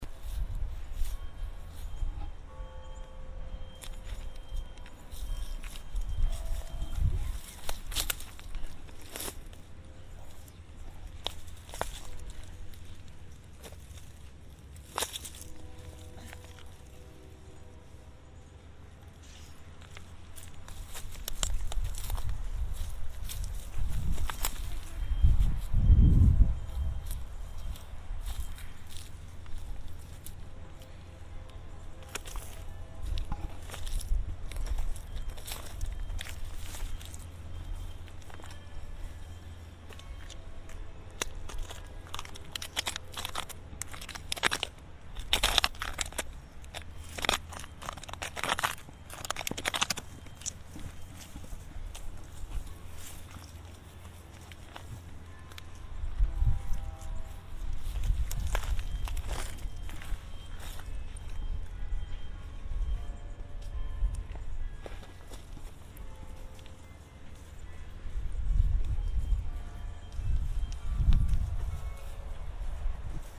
Grove ambient 6.mp3 Dancer foot steps make crunching noise at the bottom of the tree near where the roots come out, more wind, more construction beeps, scraping bark